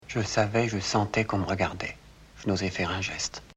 There is another effect associated with this speedup, an effect that is especially obvious to those with a well-developed musical ear: viz., the audio track of the film is playing 4% too fast, and thus 4% higher in pitch.
New Yorker VHS  New Yorker DVD  Stacked samples
It is therefore rather unfortunate that Fontaine's voice sounds quite nasal on the DVD and no longer possesses the soulful resonance the director had intended. It sounds as if he is speaking from his chest, rather than from his heart.